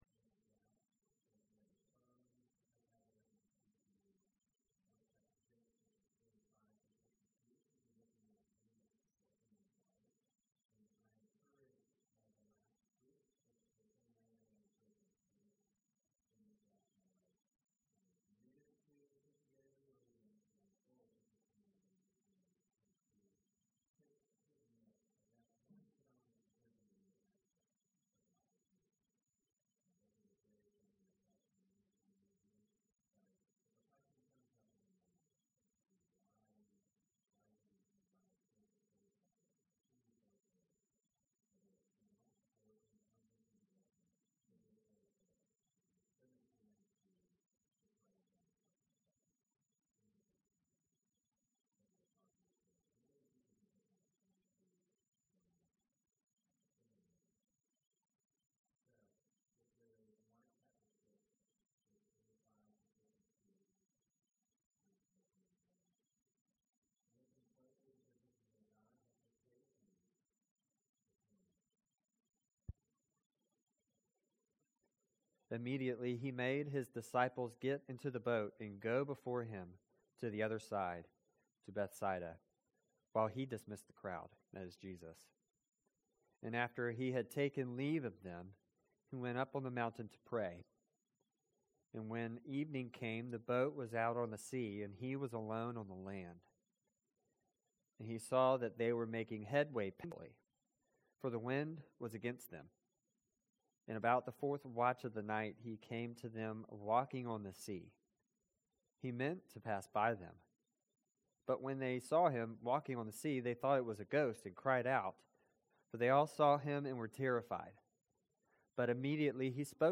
Trinity Presbyterian Church Sermons